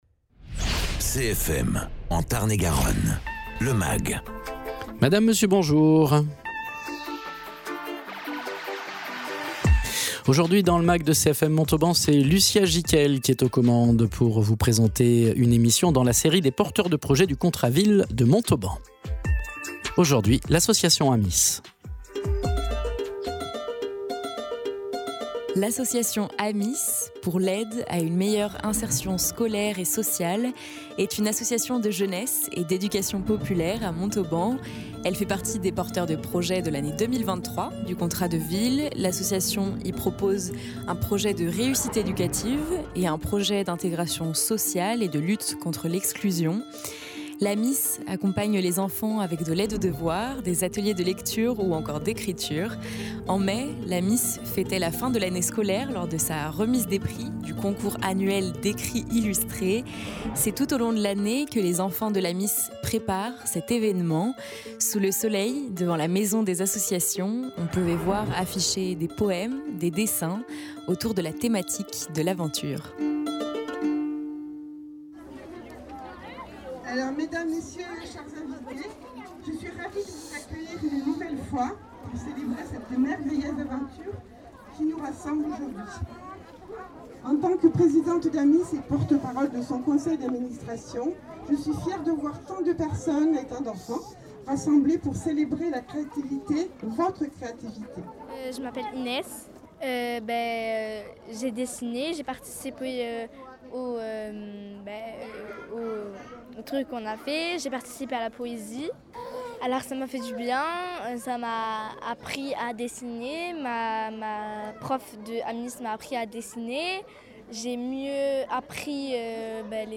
Reportage à propos de l’association AMISS, pour l’aide à une meilleure insertion scolaire et sociale, une association de jeunesse et d’éducation populaire à Montauban. Elle fait partie des porteurs de projets de l’année 2023 du Contrat de ville : l’association y propose un projet de réussite éducative et un projet d’intégration sociale et de lutte contre l’exclusion. En mai, l’AMISS fêtait la fin de l’année scolaire lors de sa remise des prix du concours annuel d’écrits illustrés.
intervenante Enfants et parents